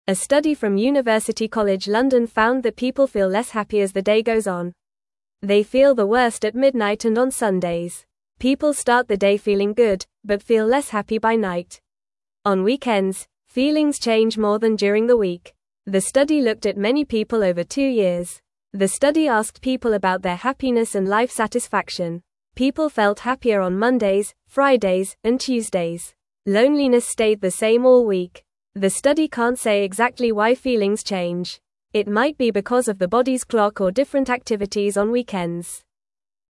Fast
English-Newsroom-Beginner-FAST-Reading-Why-People-Feel-Happier-at-the-Start-of-Days.mp3